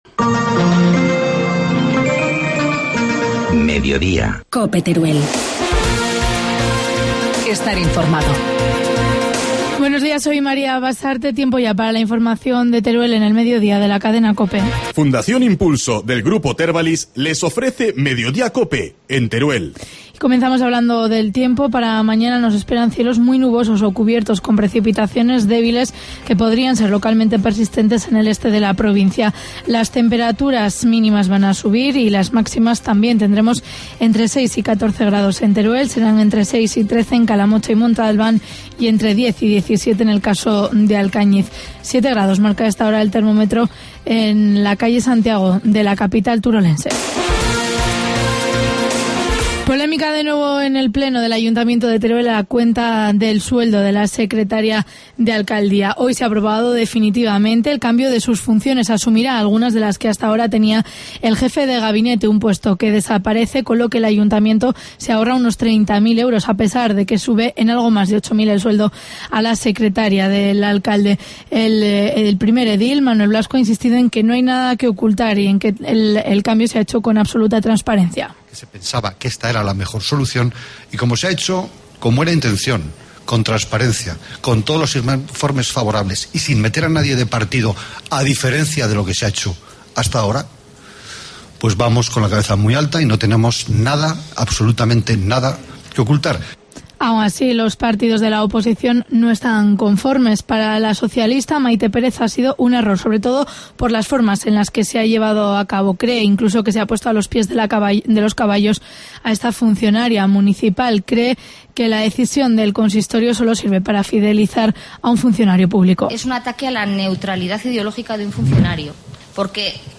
Informativo mediodía, lunes 4 de marzo